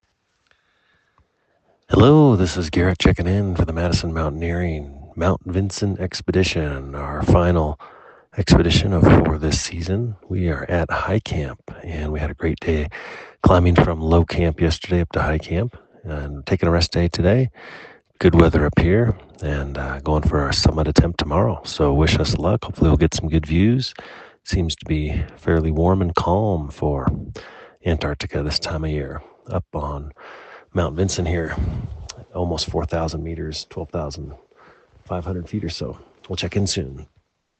• Enable the skill and add to your flash briefing to hear our daily audio expedition updates on select expeditions.